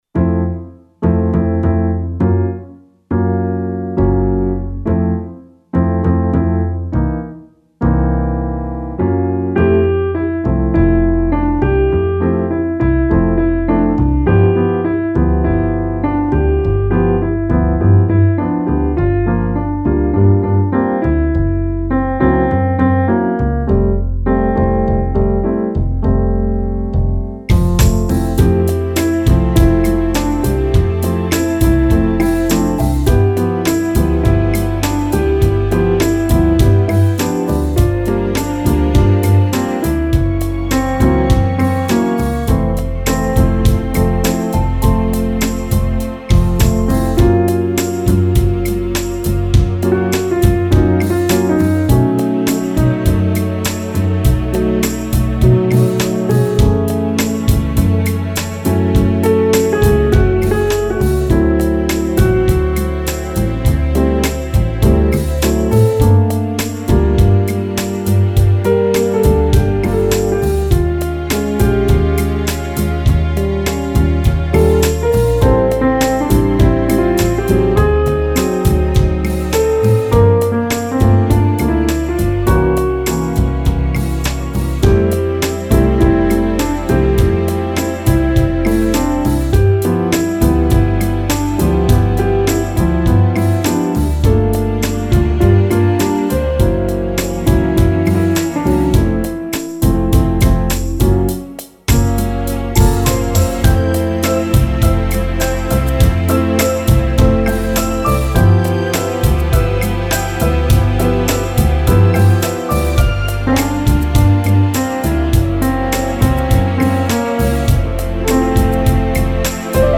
Bossa Nova Jazz
bossa nova and jazz song